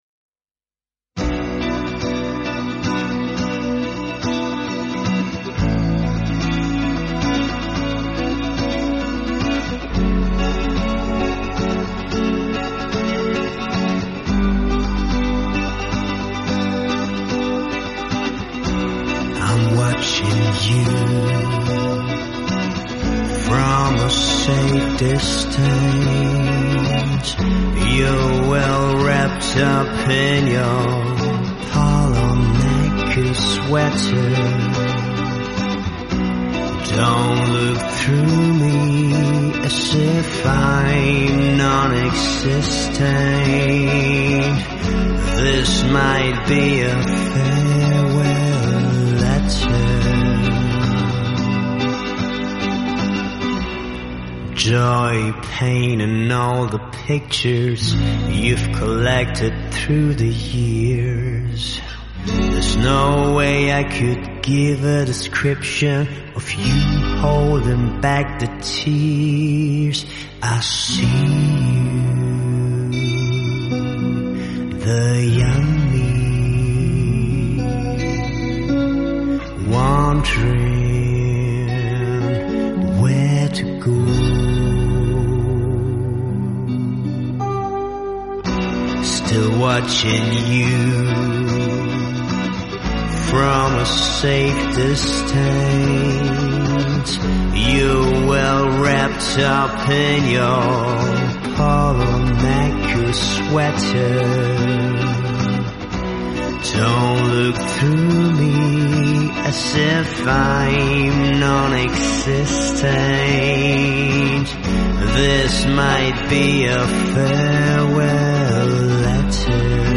Zuständigkeitsbereich: singer